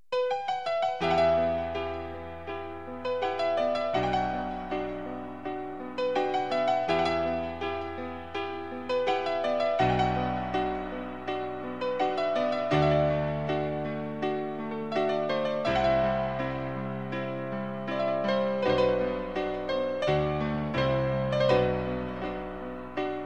Sad Ringtones